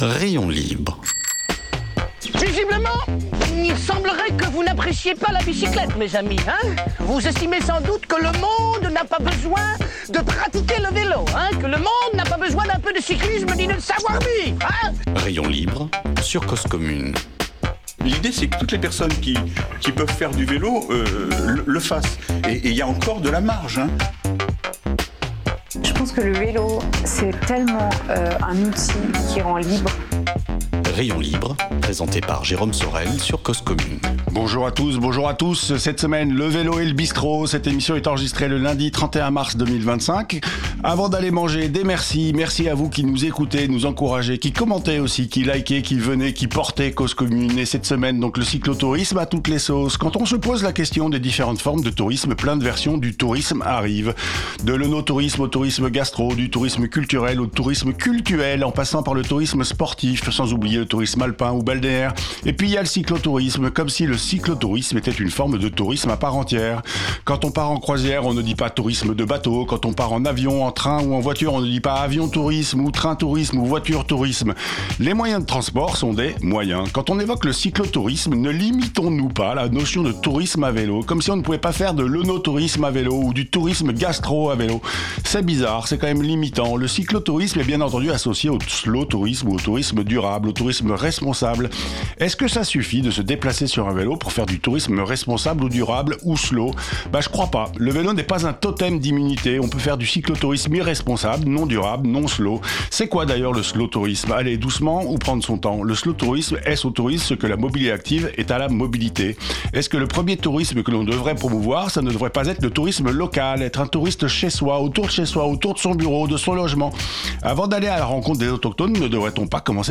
Est-ce une prise de conscience, pour cuisiner plus durable, plus local ? Après avoir écouté cette interview, peut-être filez réserver votre table au Erso.